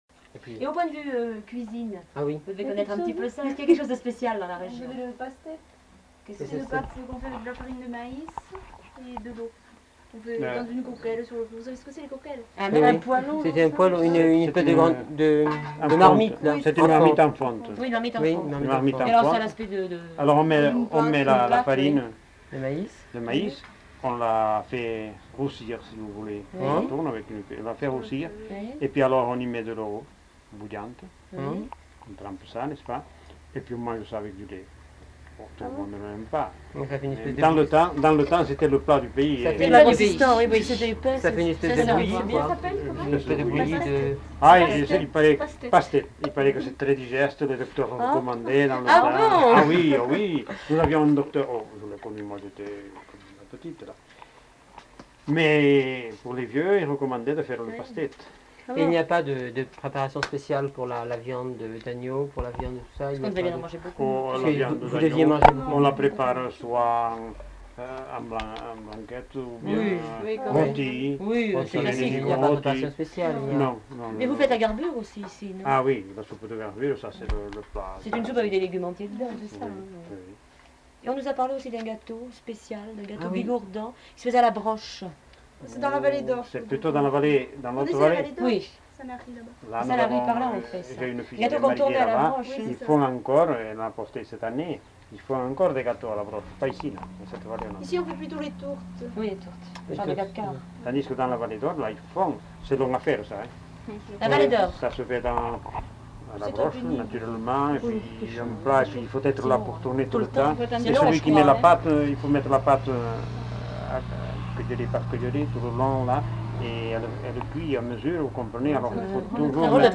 Aire culturelle : Bigorre
Lieu : Campan
Genre : témoignage thématique